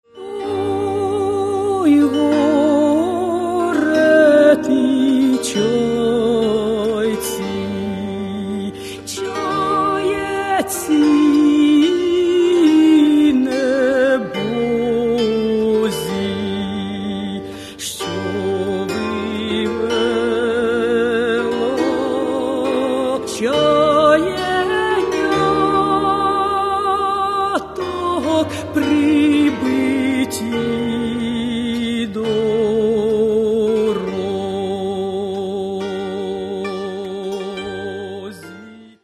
Каталог -> Народная -> Ансамбли народной музыки